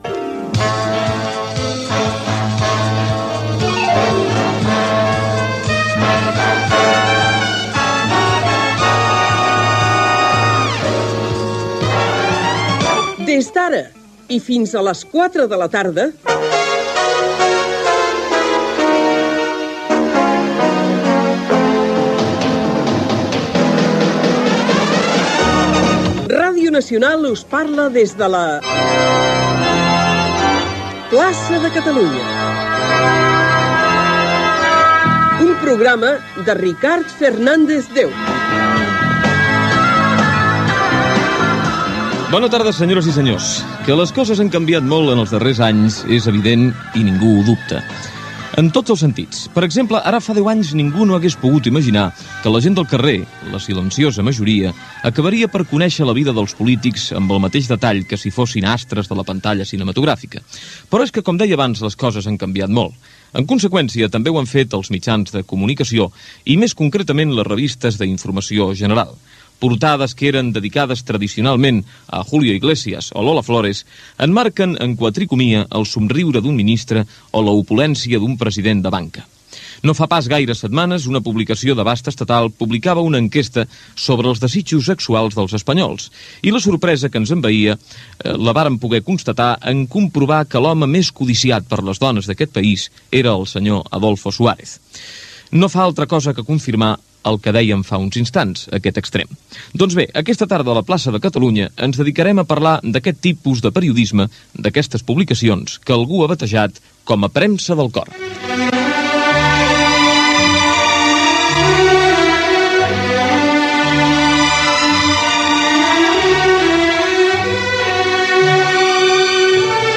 Careta del programa.
Entreteniment
Presentador/a